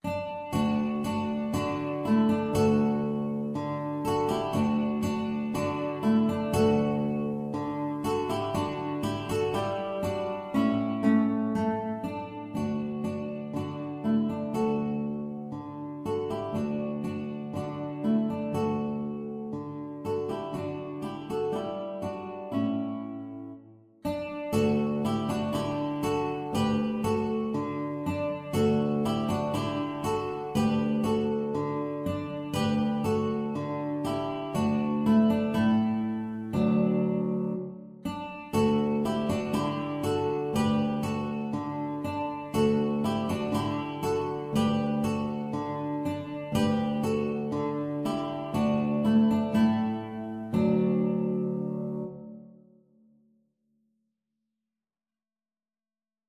D major (Sounding Pitch) (View more D major Music for Guitar Trio )
Allegro (View more music marked Allegro)
Guitar Trio  (View more Easy Guitar Trio Music)
Classical (View more Classical Guitar Trio Music)